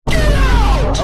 Play the iconic Get Out Vine Boom sound button for your meme soundboard!